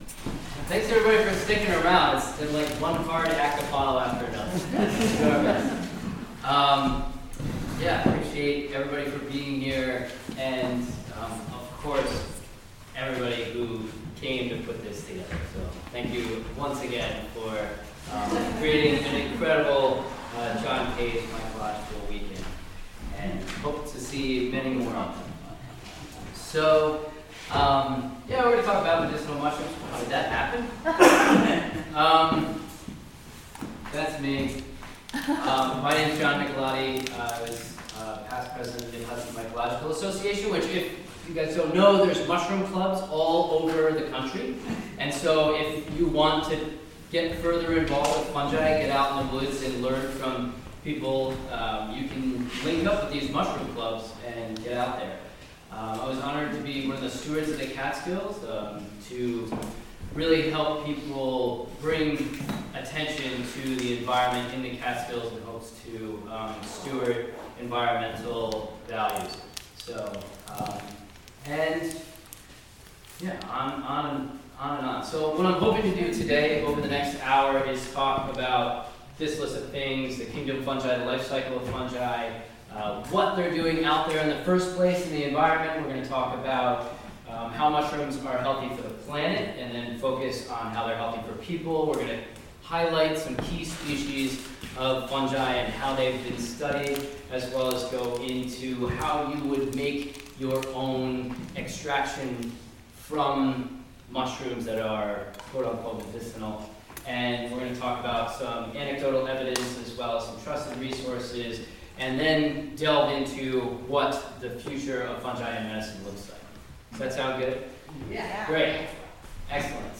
A live stream presented in partnership with the Jo...